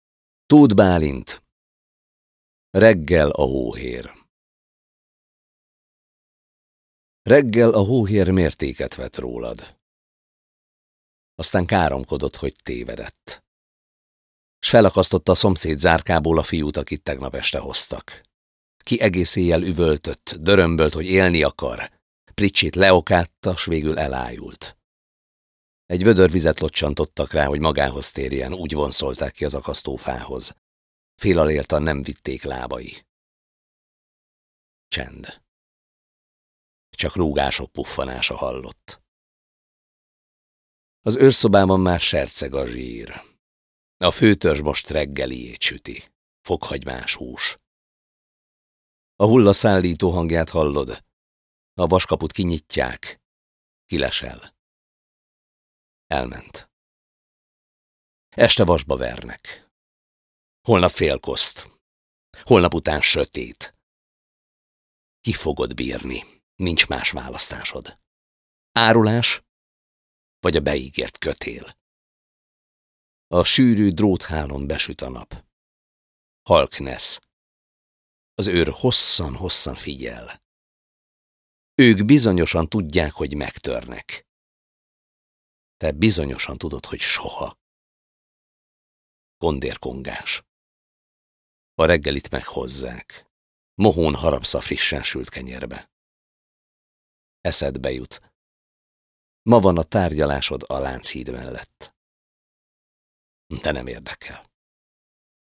Az 1956-os forradalom és szabadságharc eseményeinek 60. évfordulójára emlékezve újszerű, tematikus, hangos versantológiát jelentetünk meg, a 21. század igényeihez igazodva online hangoskönyv formájában, amely lehetőséget biztosít a látogatónak az interaktív jelenlétre.
A felvételek a MaRecord (Luxfunk) és a Nemzeti Színház stúdiójában készültek.